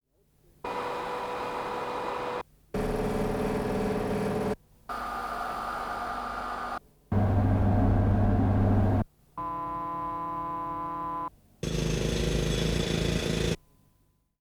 Es sind sechs verschiedene Industriegeräusche zu hören, die alle einen Schalldruckpegel von 80dB(A) besitzen. Dennoch variieren die Lautstärken der einzelnen Geräusche stark.
Demonstration: Sechs Schalle mit gleichem A-bewertetem Schalldruckpegel dB(A)
Sechs_Schalle_gleiche_A_Bewertung.WAV